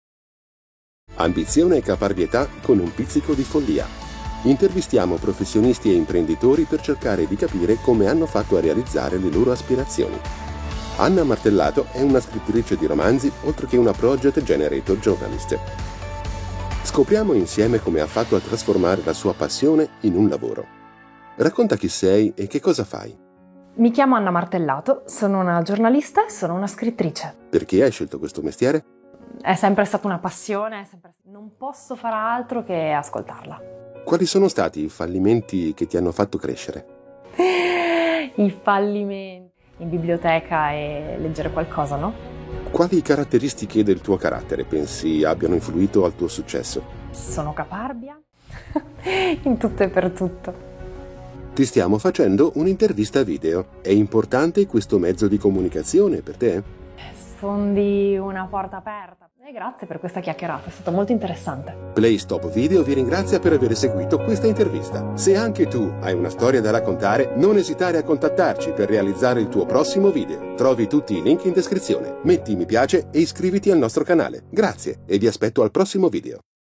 Male
Adult (30-50)
Corporate
Video Interview